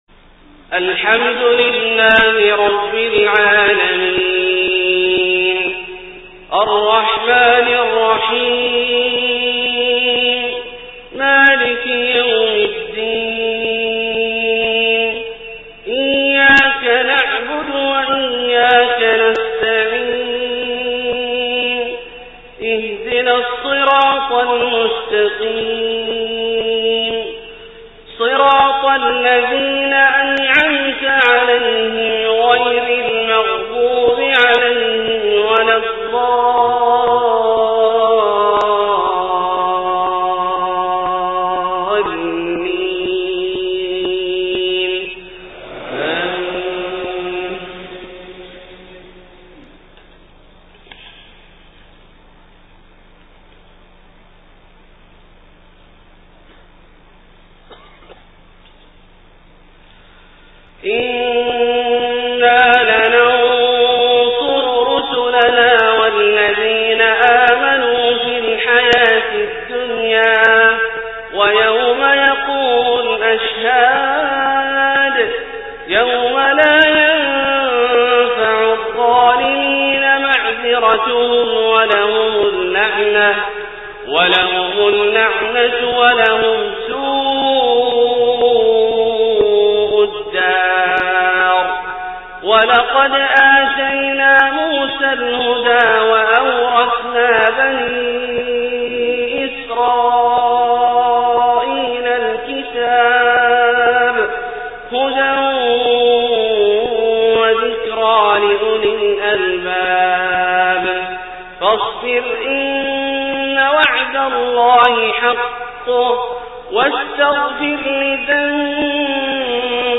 فجر 1-3-1429 من سورة غافر {51-66} > ١٤٢٩ هـ > الفروض - تلاوات عبدالله الجهني